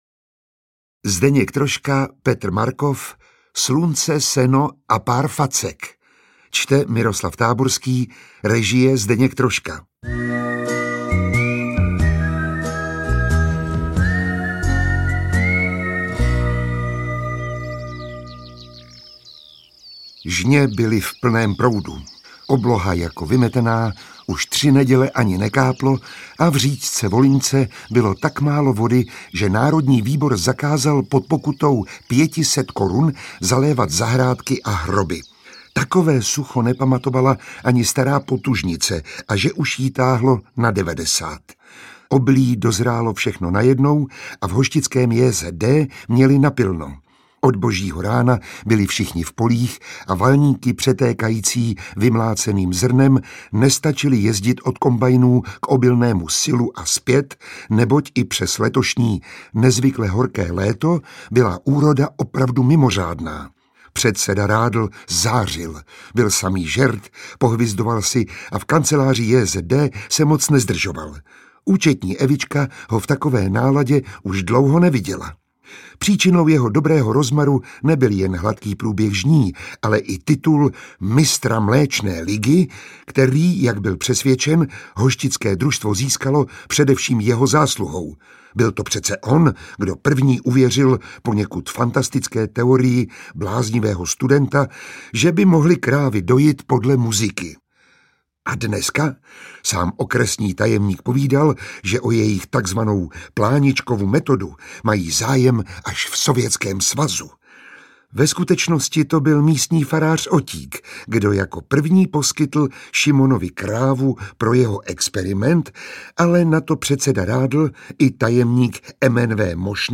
Interpret:  Miroslav Táborský
Literární zpracování legendárních filmových komedií v audioknižní podobě. Čte Miroslav Táborský, režie Zdeněk Troška.